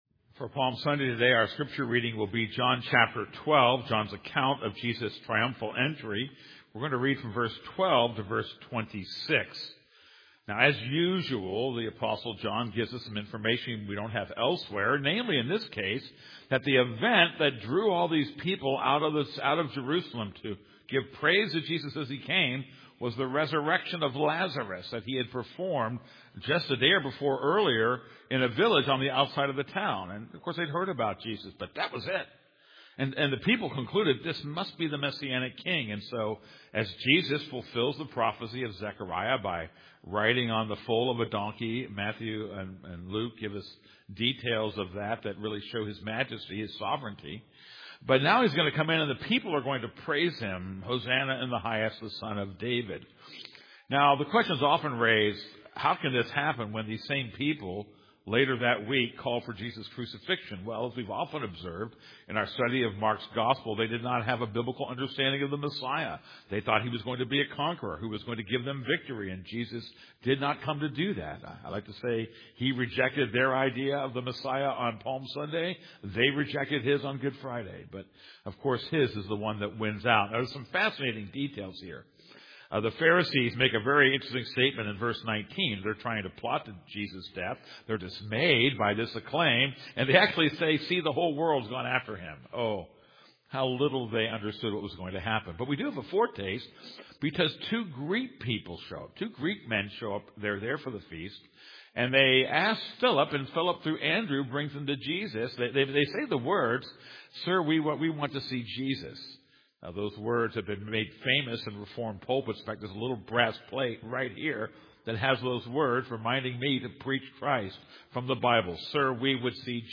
This is a sermon on John 12:12-26.